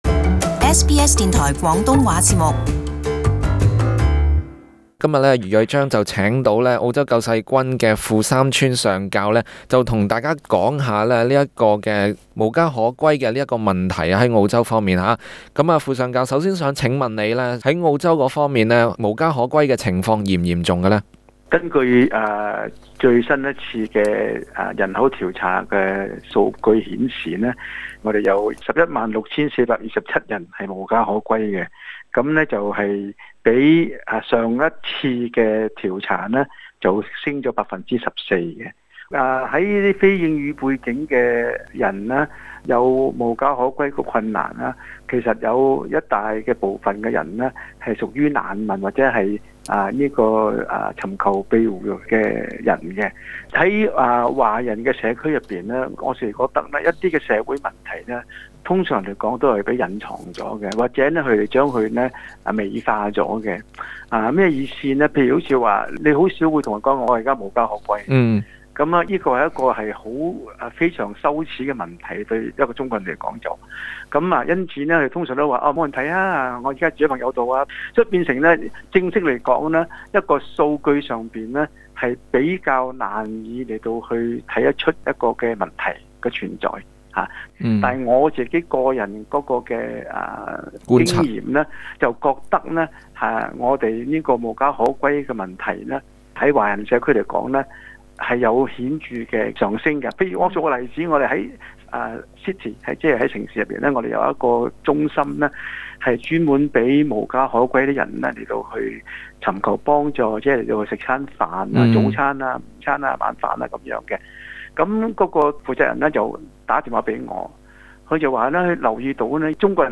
【時事專訪】多點愛心、了解無家可歸者